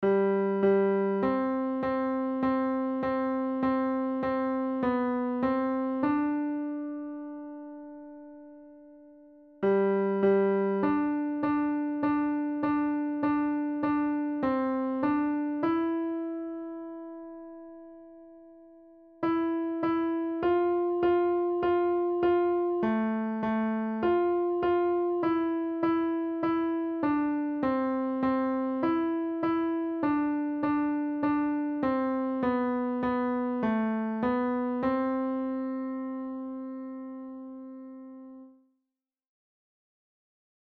On the piano, play